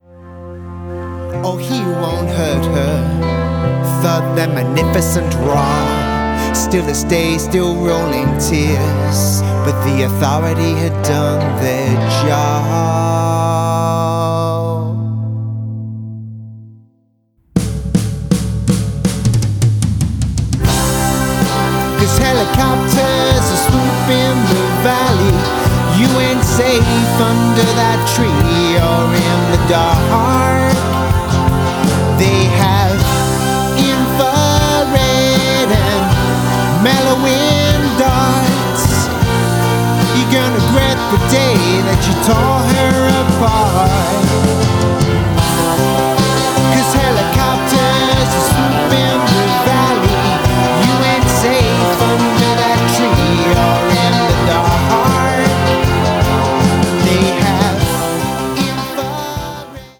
ein sehr schöner, ruhiger Song mit viel Power!